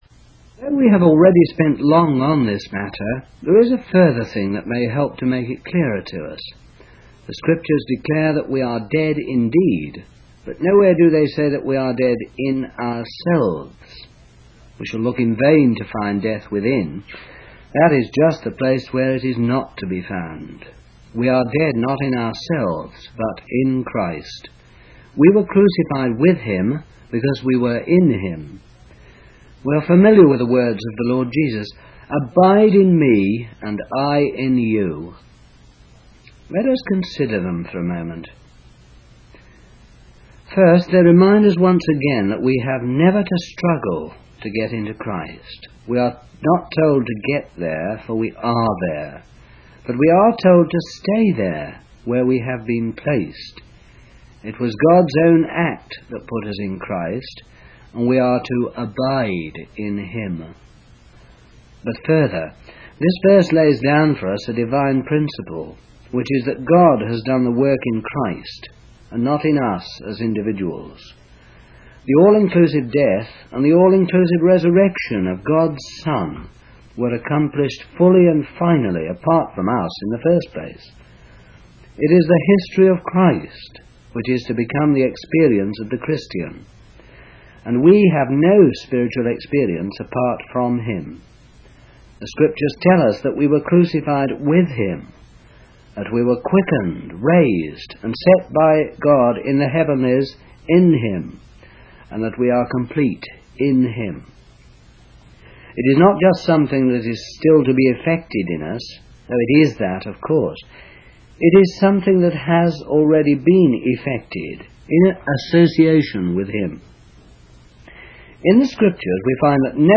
Two Creations (Reading) by Watchman Nee | SermonIndex